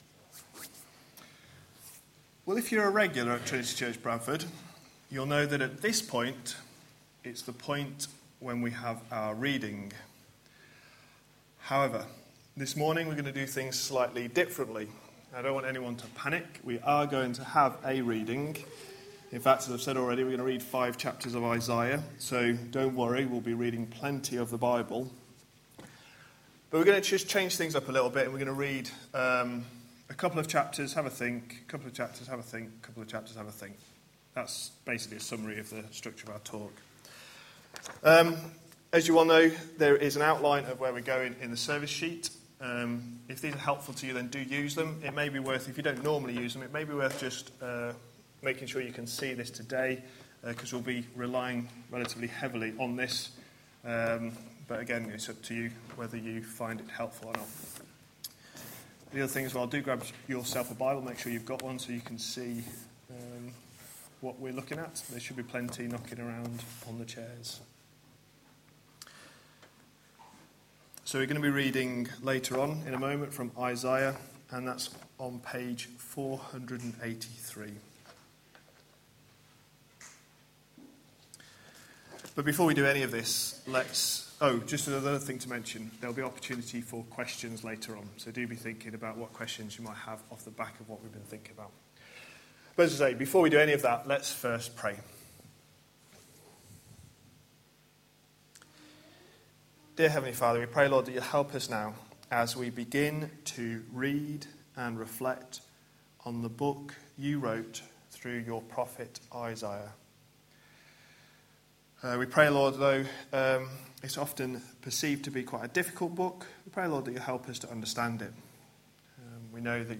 A sermon preached on 22nd March, 2015, as part of our No one compares series.